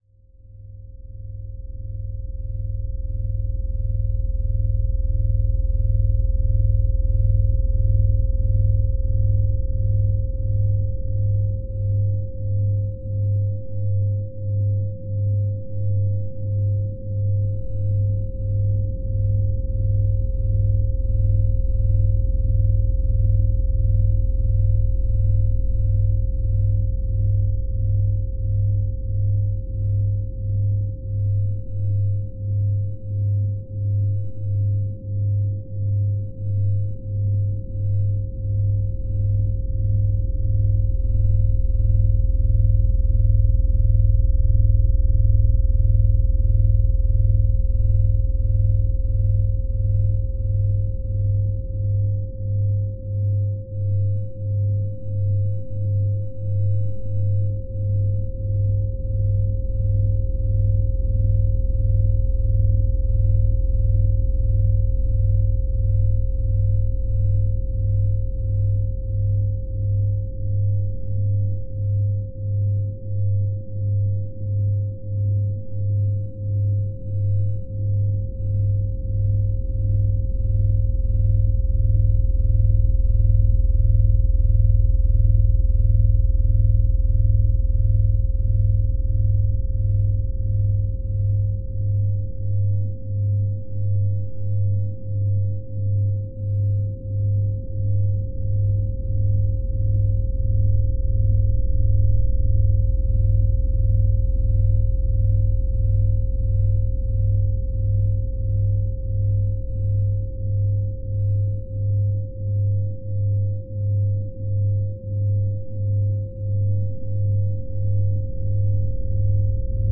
1.5hz delta + slow spiral.